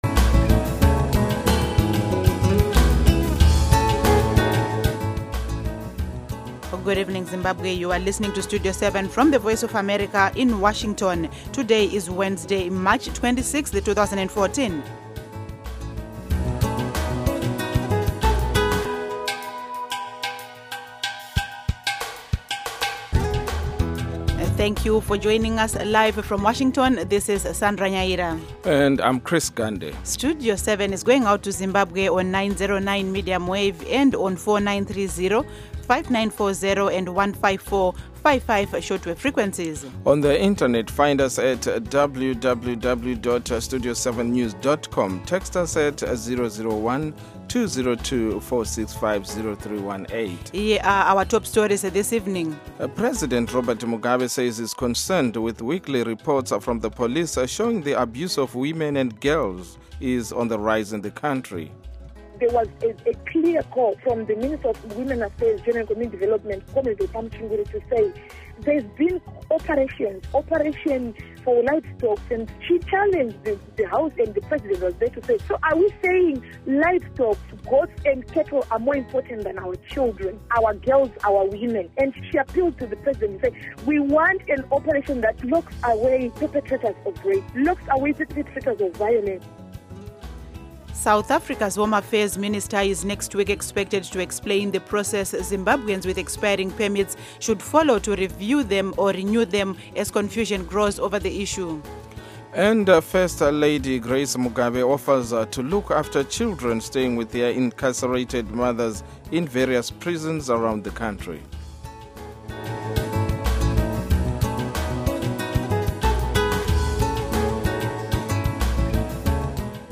Studio 7 for Zimbabwe provides comprehensive and reliable radio news seven days a week on AM, shortwave and satellite Schedule: Monday-Friday, 7:00-9:00 p.m., Saturday-Sunday, 7:00-8:00 p.m., on Intelsat 10 repeats M-F 9-11 p.m. Local Time: 7-9 p.m. UTC Time: 1700-1900 Duration: Weekdays: 2 hours; Weekends: 1 hour Listen: MP3